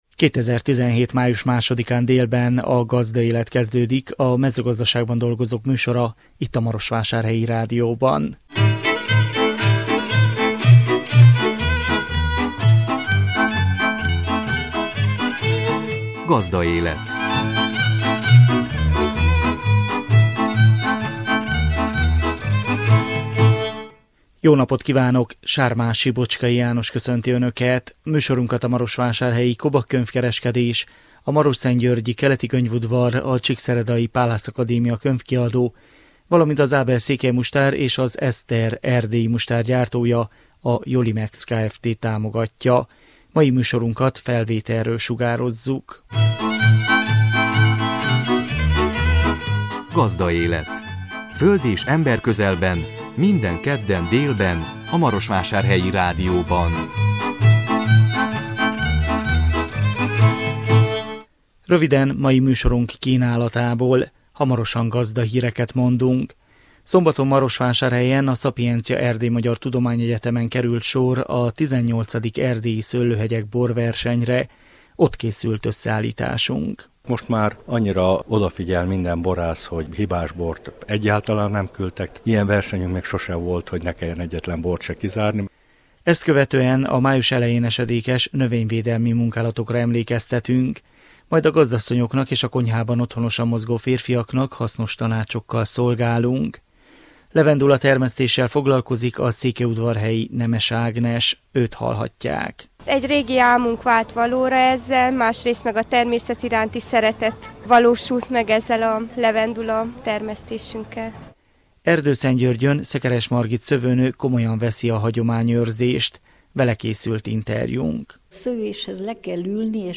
A 2017 május 2-án jelentkező műsor tartalma: Gazdahírek, Szombaton Marosvásárhelyen a Sapientia EMTE-en került sor a XVIII. Erdélyi Szőlőhegyek borversenyre. Ott készült összeállításunk. Ezt követően a május elején esedékes növényvédelmi munkálatokra emlékeztetünk.